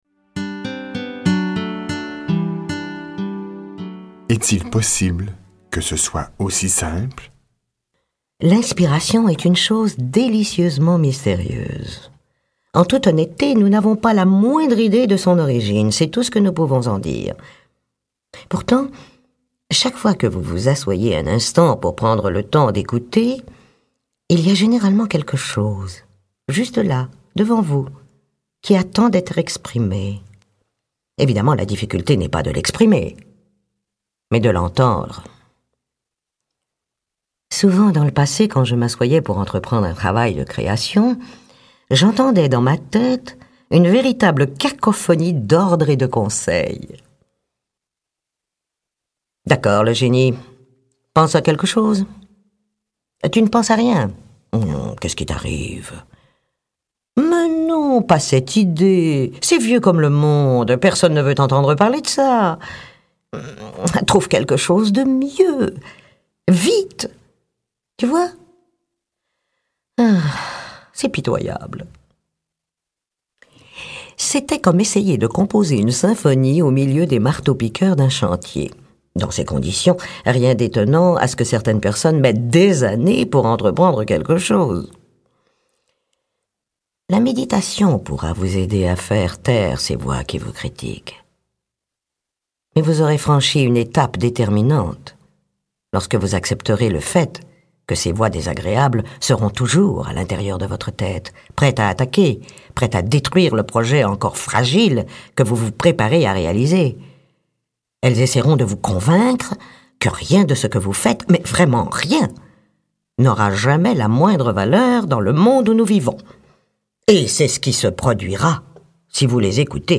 Lire un extrait - Qu'attendez-vous pour être heureux ? de Falter-Barns Suzanne